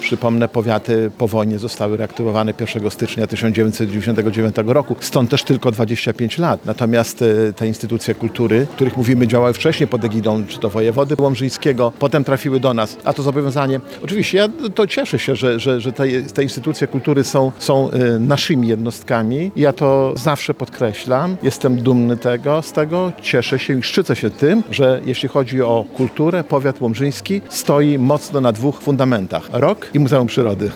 Podczas uroczystej gali zorganizowanej w Filharmonii Kameralnej im. Witolda Lutosławskiego, starostwo łomżyńskie świętowało potrójny jubileusz.
Jesteśmy dumni z działalności instytucji kultury naszego Powiatu, jednocześnie dziękujemy wszystkim, którzy przyczyniali się do ich rozwoju– mówi Starosta Łomżyński, Lech Szabłowski.